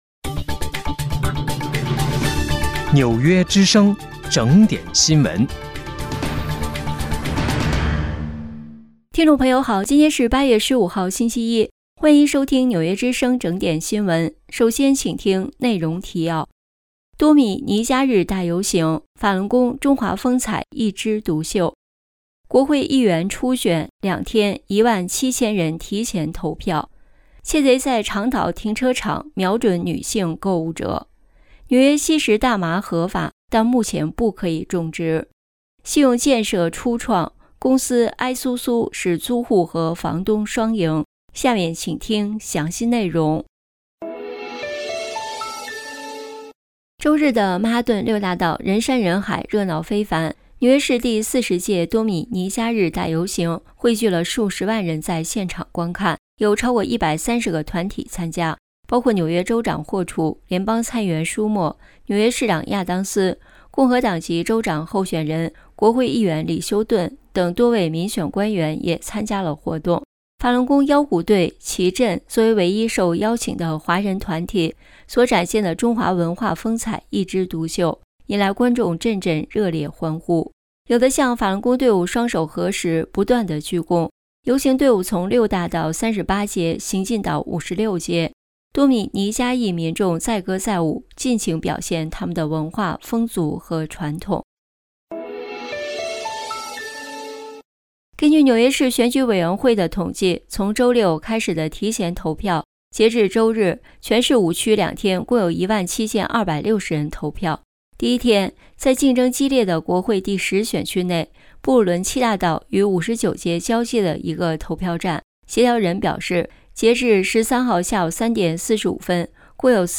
8月15日（星期一）纽约整点新闻